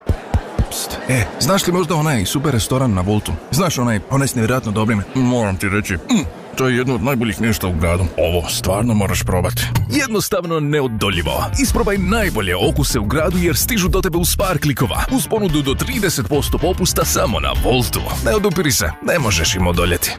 Comercial, Seguro, Amable
Comercial